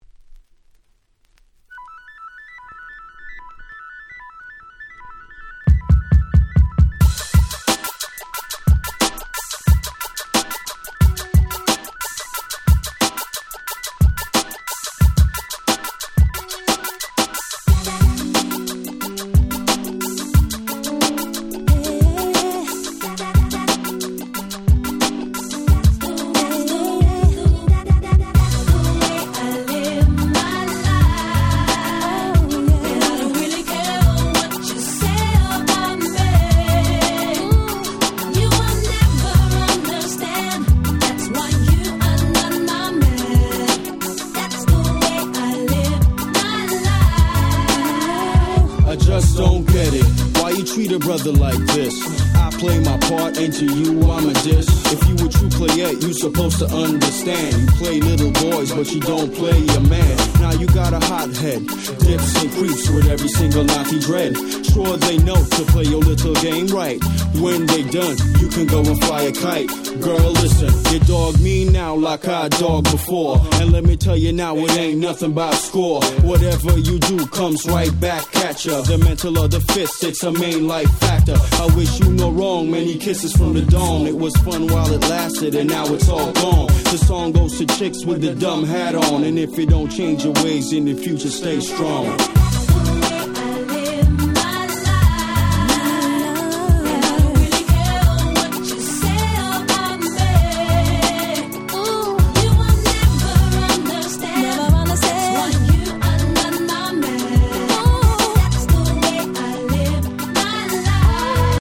00’ Nice Euro Rap !!
キャッチー歌ラップ人気盤！！
フリップダスクリップ Euro G ユーロジー キャッチー系